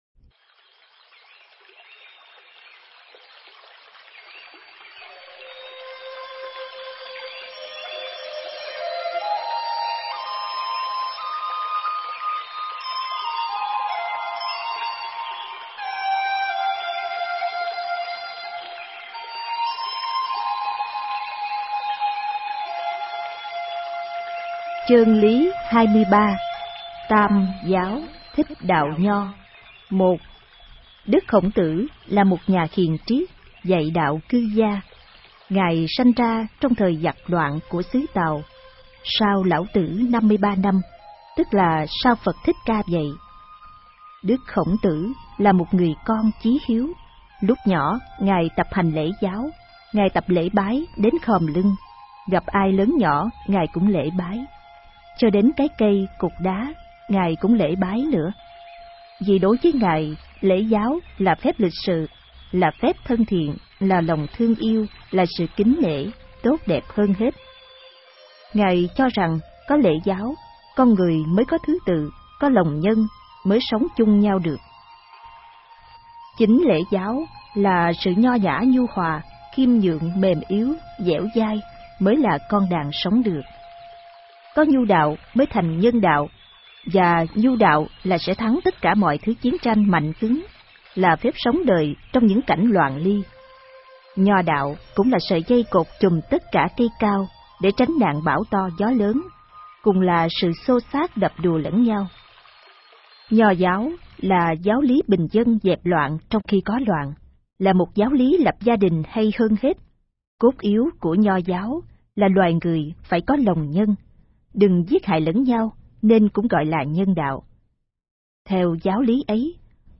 Nghe sách nói chương 23. Tam Giáo